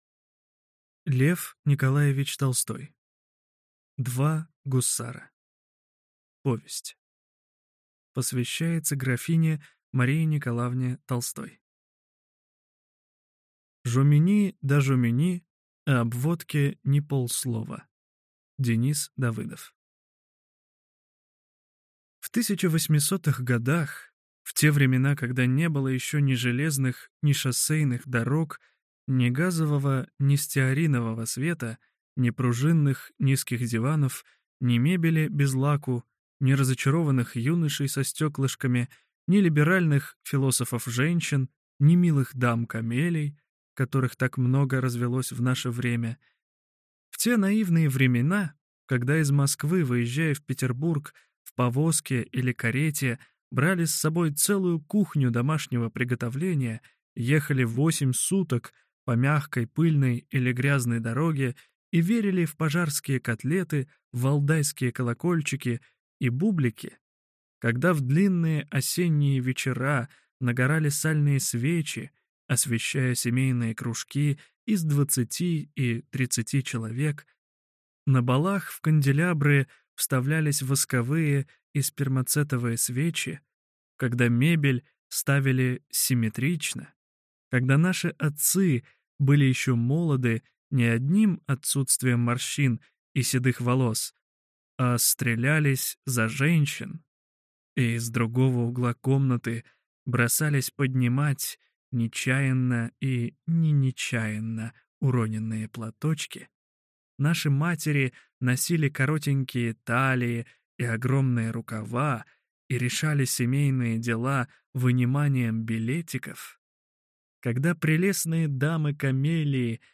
Аудиокнига Два гусара | Библиотека аудиокниг